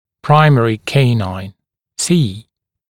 [dɪ’sɪdjuəs ‘keɪnaɪn], [‘praɪmərɪ ‘keɪnaɪn], [siː][ди’сидйуэс ‘кейнайн], [‘праймэри ‘кейнайн], [сиː]молочный клык